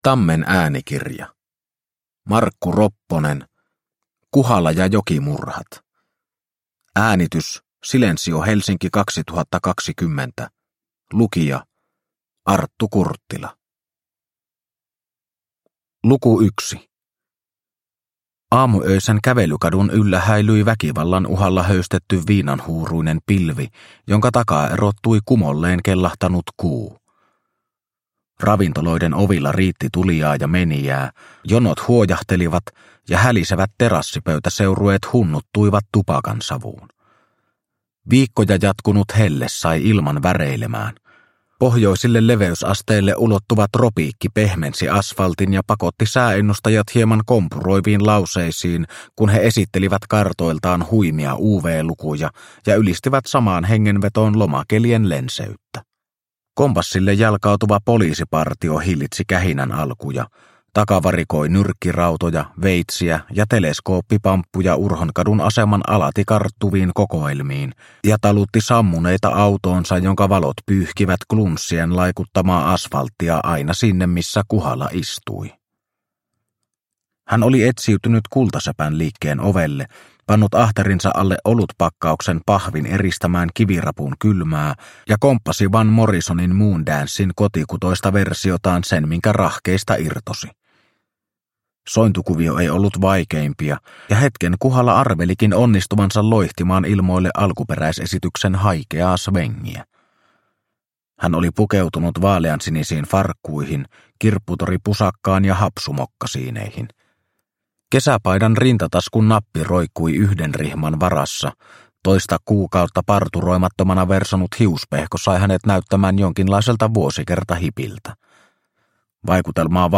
Kuhala ja jokimurhat – Ljudbok – Laddas ner